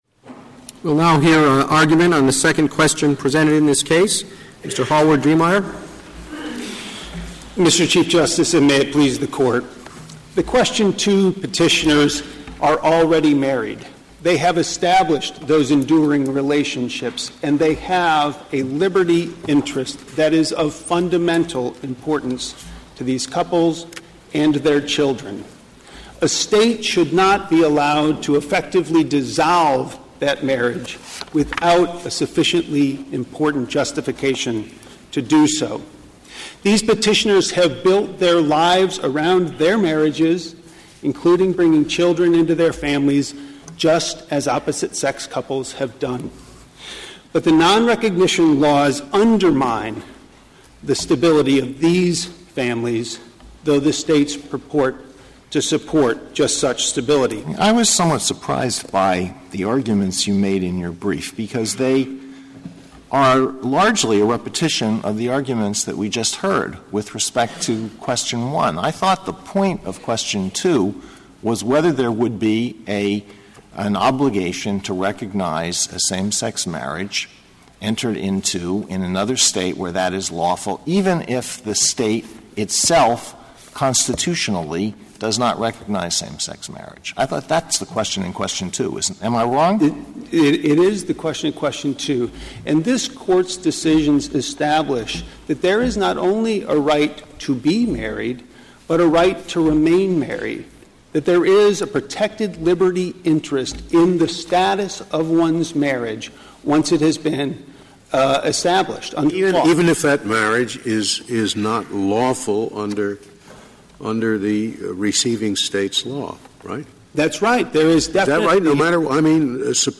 Part two of this morning's Supreme Court arguments on same sex marriage.